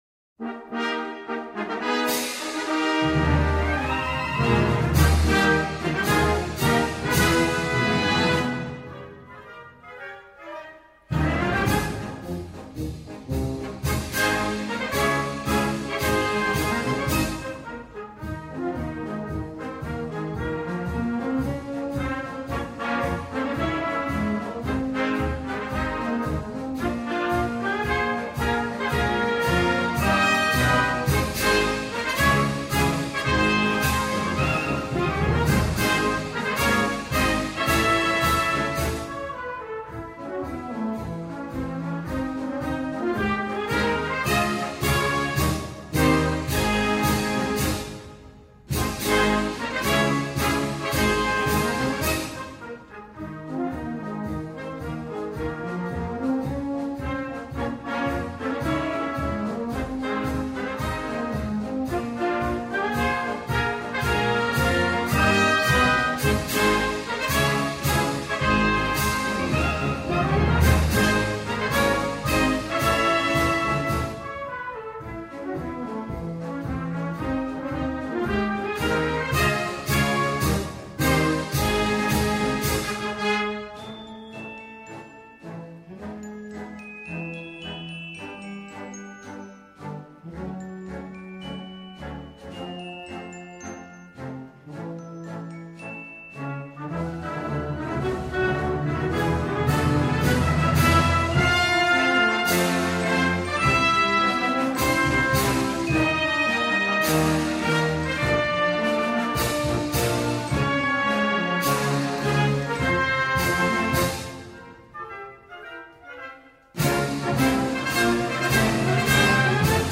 Gattung: Konzertmarsch für Blasorchester
Besetzung: Blasorchester